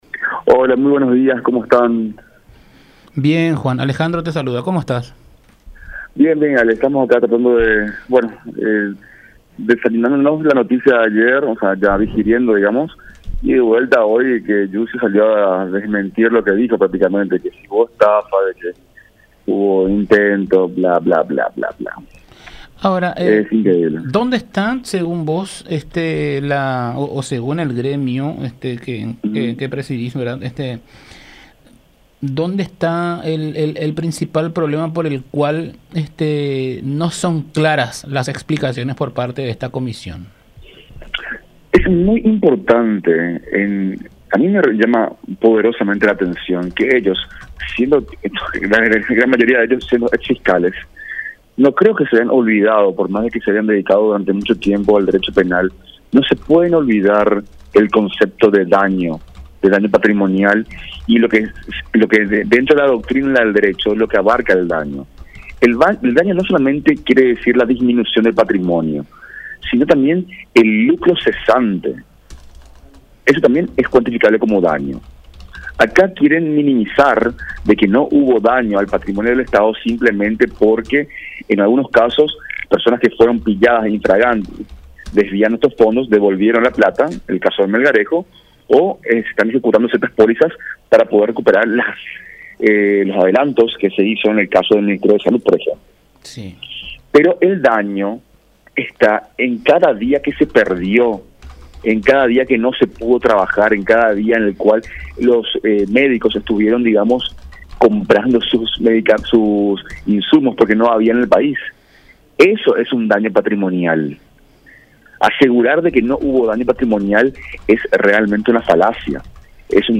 en comunicación con La Unión R800 AM.